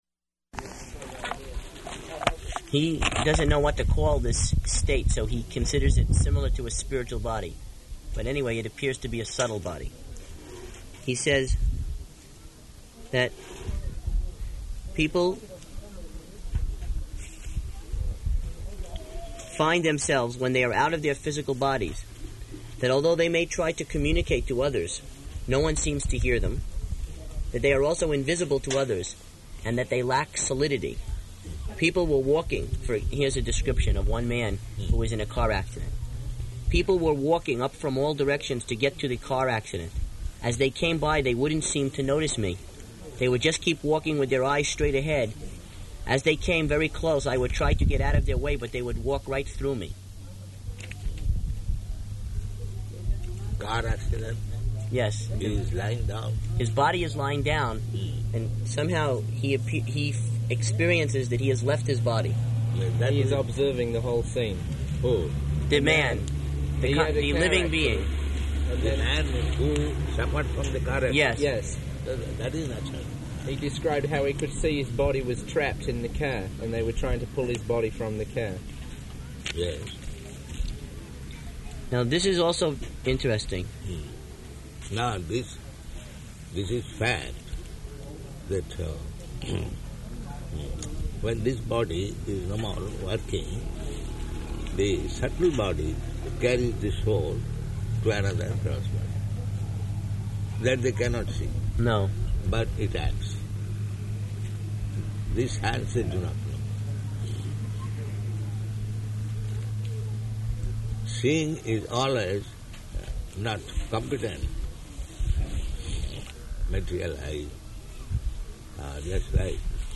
Room Conversation
Type: Conversation
Location: Bhubaneswar